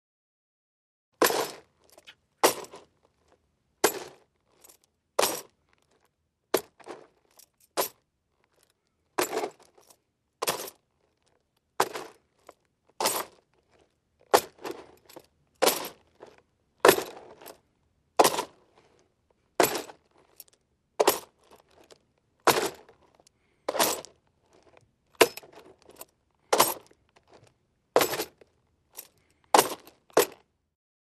FootstepsBootGrave PE770202
FOLEY FOOTSTEPS BOOTS: EXT: Slow western boot walk on gravel with spurs.